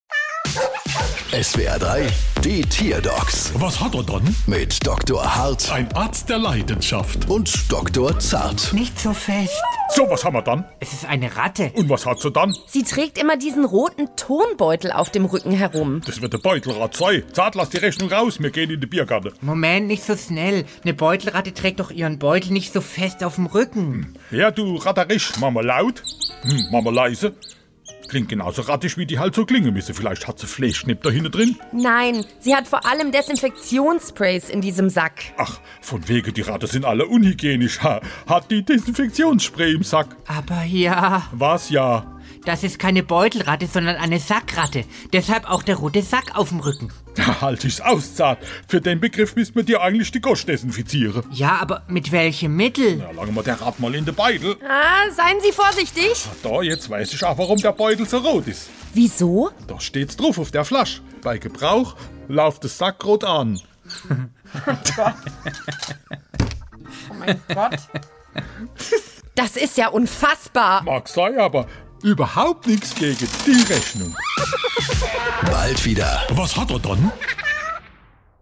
SWR3 Comedy Die Tierdocs: Ratte mit Turnbeutel